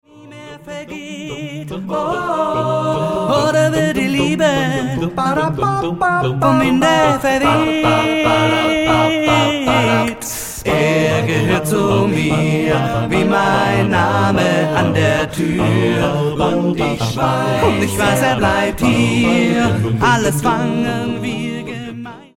Studio-Tonträger